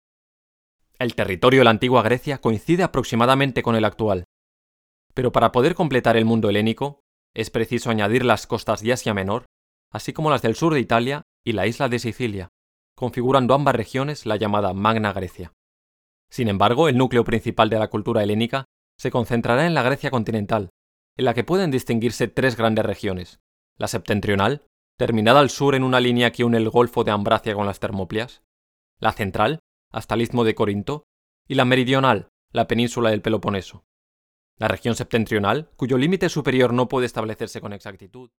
I deliver best results with my very confident, smooth and professional tone for narration and technical copy. Also, I sound friendly, warm, sincere, honest and natural.
kastilisch
Sprechprobe: Werbung (Muttersprache):